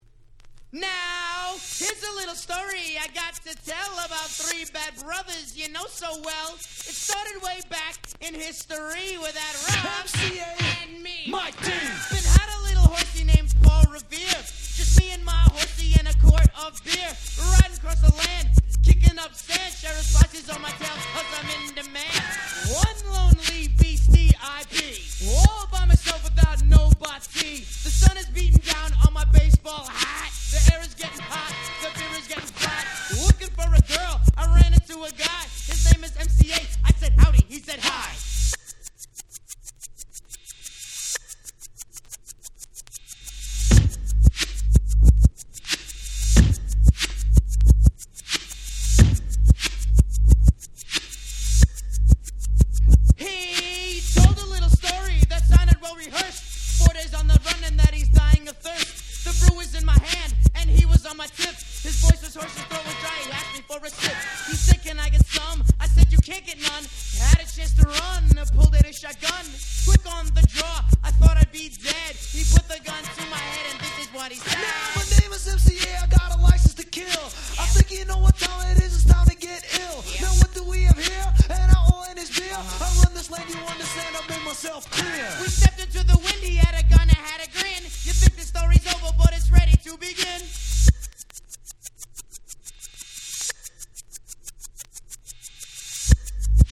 86' Old School Hip Hop Super Classics !!